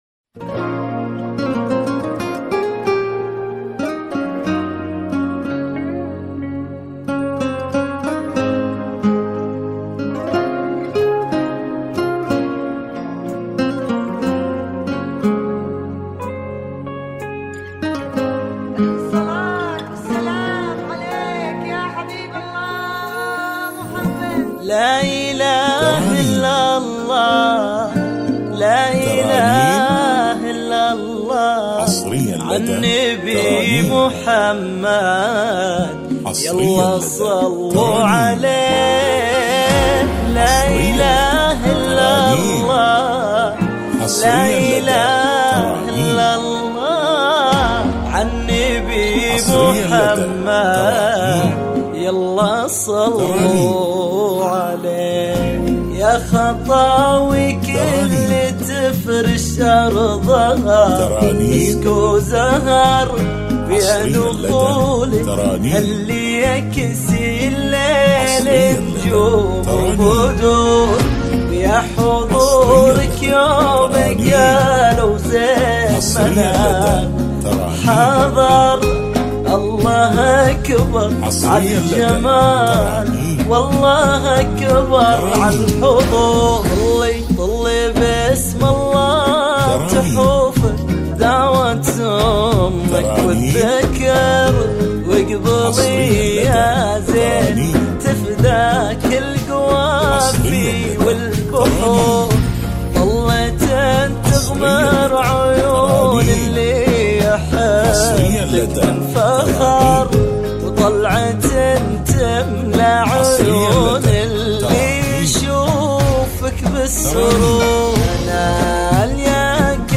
زفات بدون حقوق